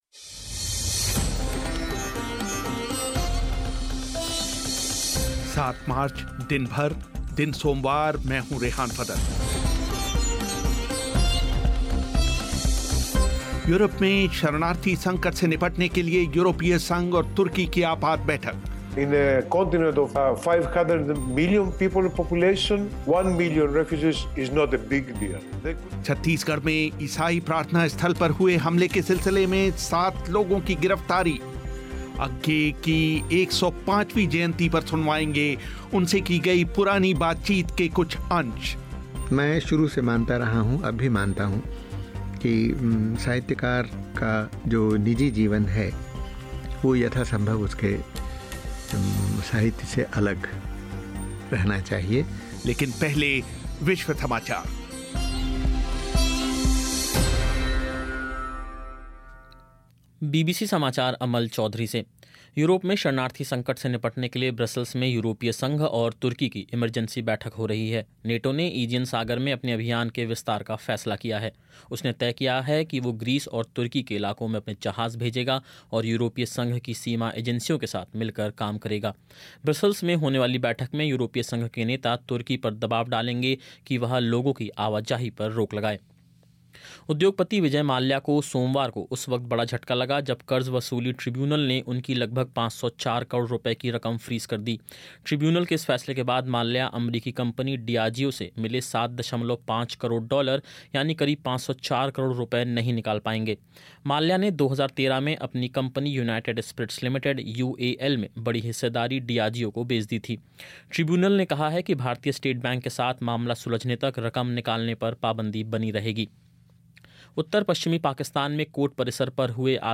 अज्ञेय की 105वी जयंती पर सुनवाएंगे उनसे की गई एक पुरानी बातचीत होंगे आपके पत्रों के उत्तर और खेल समाचार भी